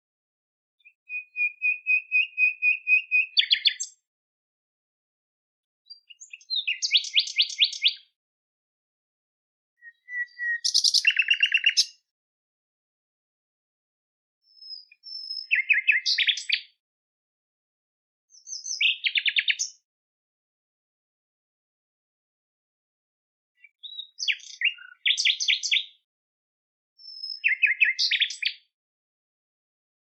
Noyer noble, concert rafraîchissant du rossignol
Le chant du Rossignol est d’une beauté rare. Riche en nuances, d’une virtuosité naturelle, il incarne à lui seul la poésie sonore de la nature.
• Son : Chant du Rossignol
Satellitebox_Nightingale_soundfile_30s.mp3